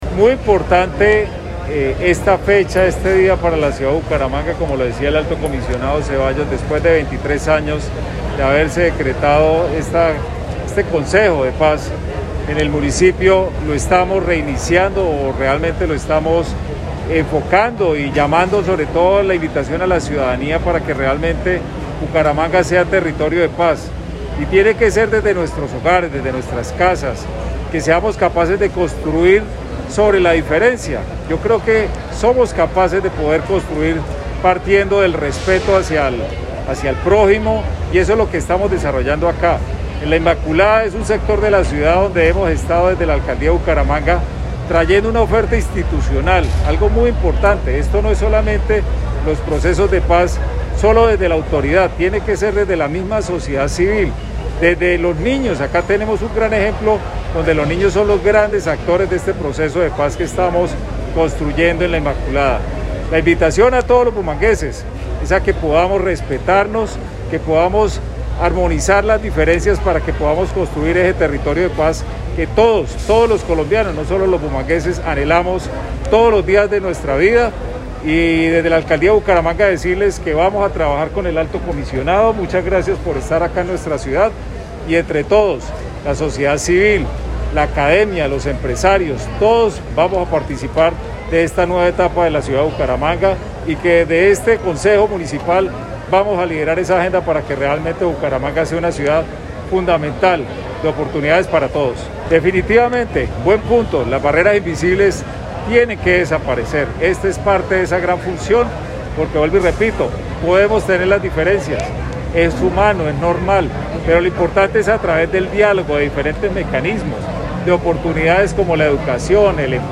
En el barrio La Inmaculada, bajo el liderazgo del alcalde de los bumangueses, Juan Carlos Cárdenas Rey, se realizó por primera vez en la historia la instalación del Consejo Municipal de Paz, Reconciliación y Convivencia.
Juan-Carlos-Cardenas-alcalde-de-Bucaramanga.mp3